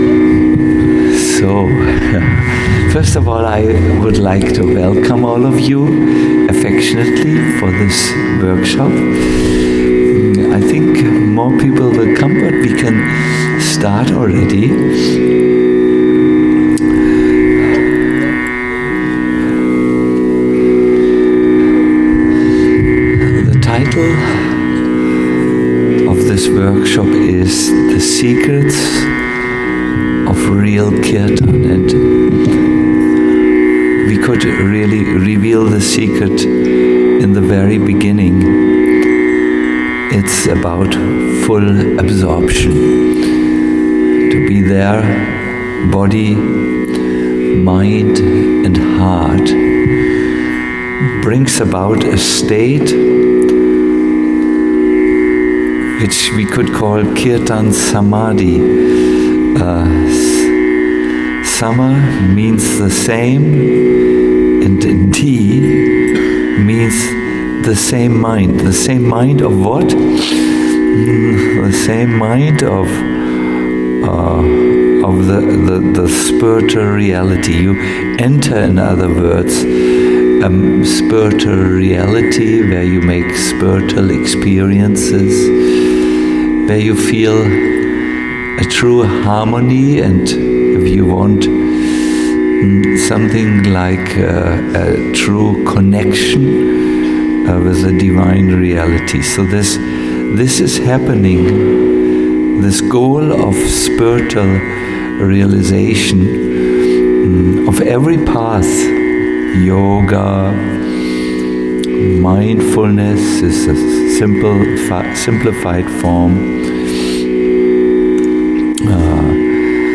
Kirtan Mela Workshop - The Secrets of Real Kirtan - a lecture
A lecture